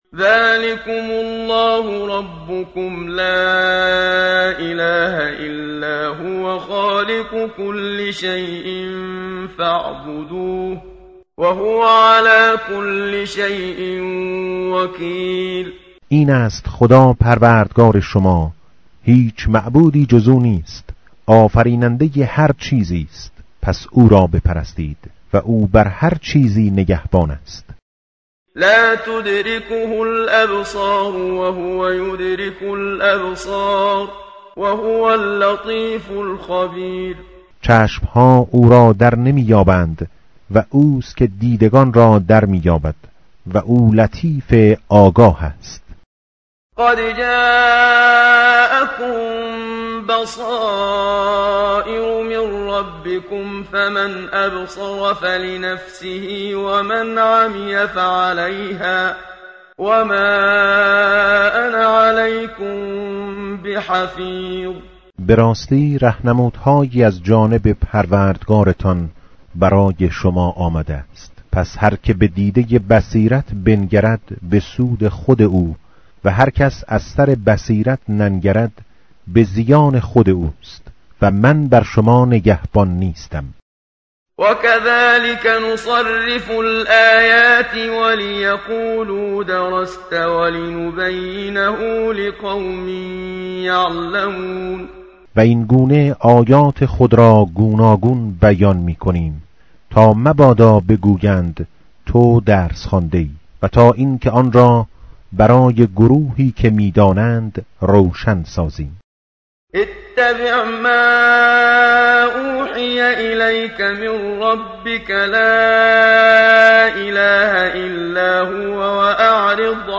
متن قرآن همراه باتلاوت قرآن و ترجمه
tartil_menshavi va tarjome_Page_141.mp3